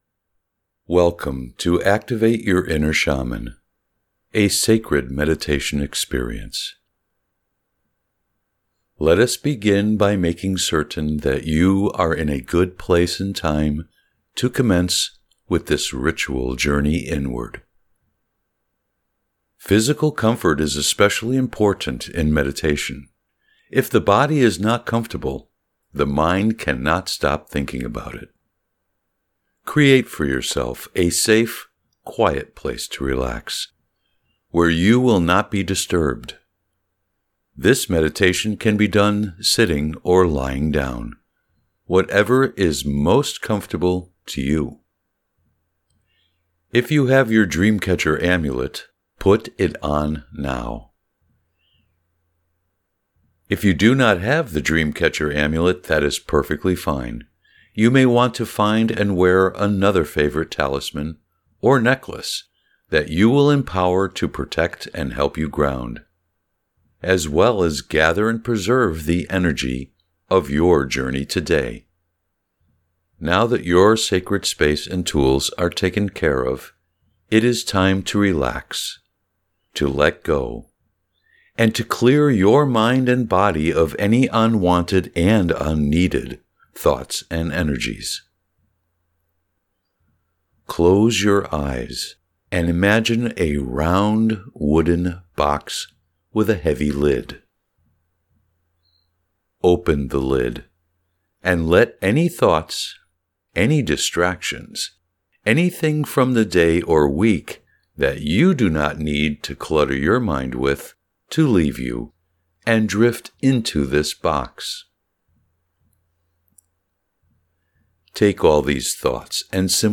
Download Your Abundance Alchemy Candle Magic Ritual Here This ritual meditation was recorded specifically to help you activate your natural ability to attract and receive abundance in the coming year.
Activate+your+inner+shaman+guided+meditation7-3.mp3